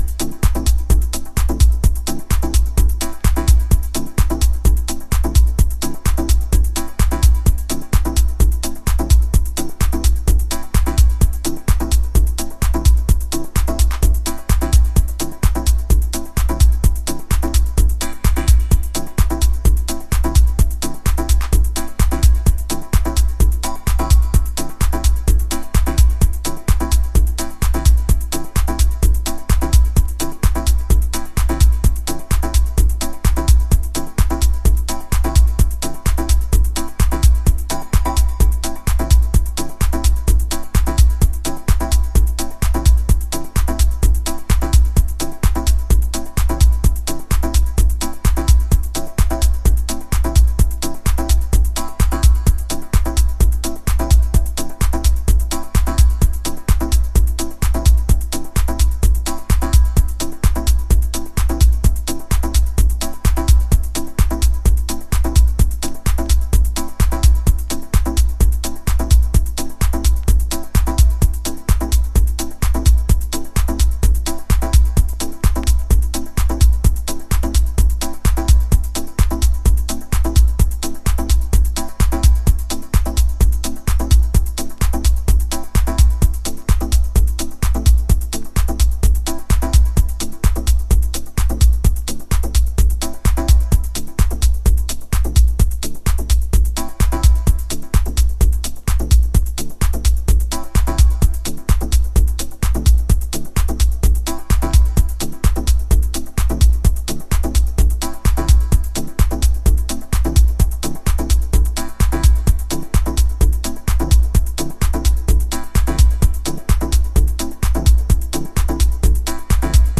House / Techno
ハウスとテクノの間をいくようなトラックス。